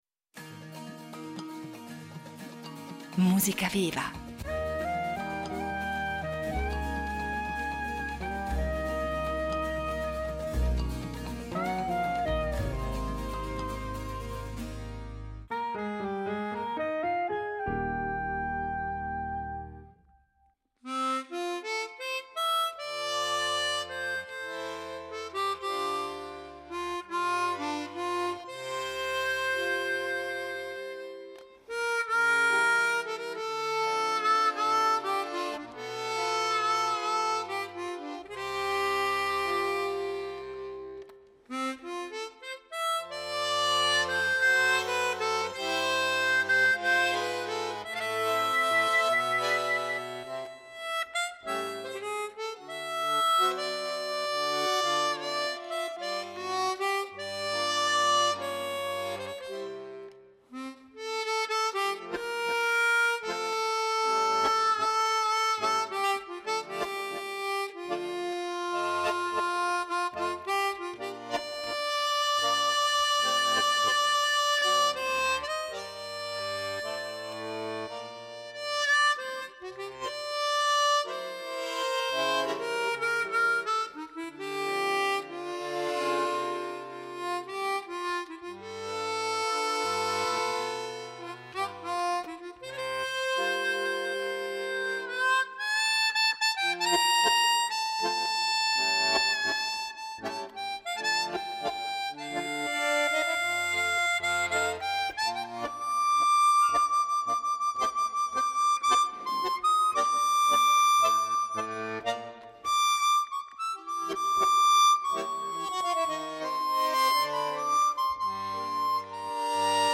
armonica cromatica e fisarmonica
folk-jazz rilassato